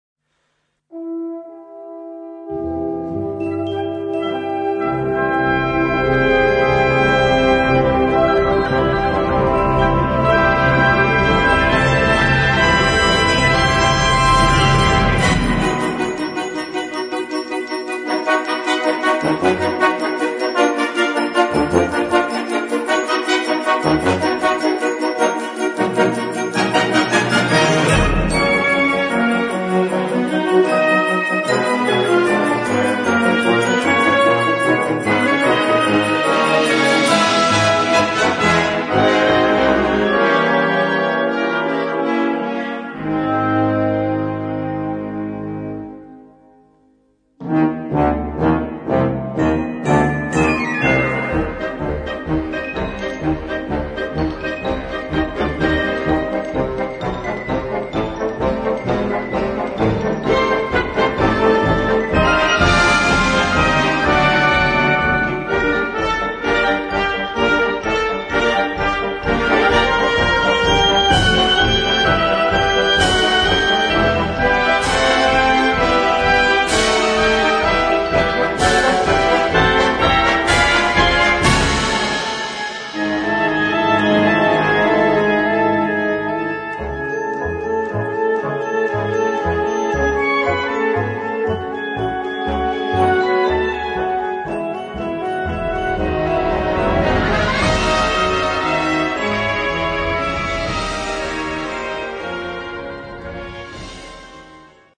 Partitions pour orchestre d'harmonie, ou brass band.